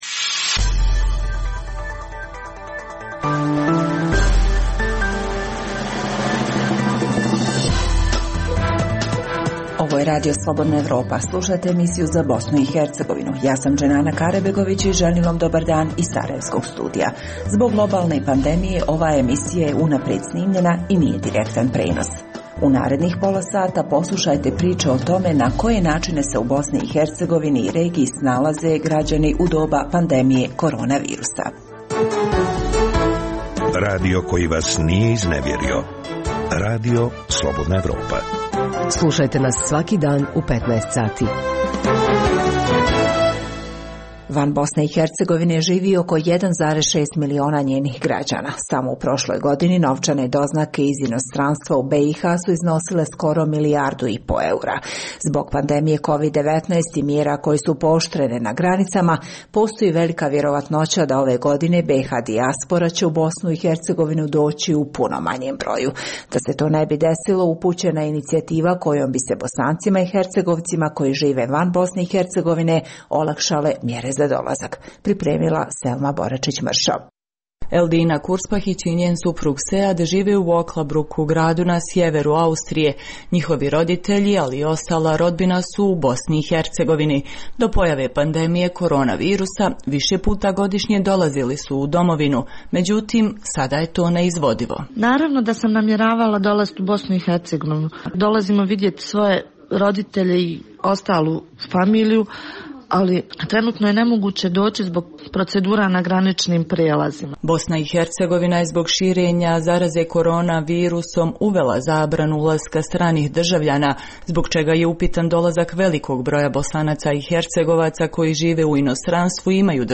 Zbog pooštrenih mjera kretanja u cilju sprječavanja zaraze korona virusom, ovaj program je unaprijed snimljen. Poslušajte na koje načine se građani BiH i regiona snalaze u vrijeme ove epidemije.